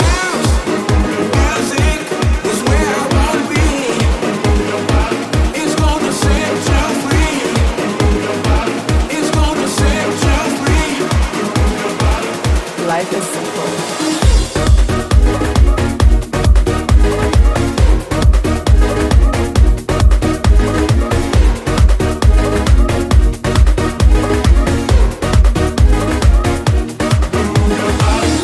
Genere: pop,dance,afrobeat,house,latin,edm,remixhit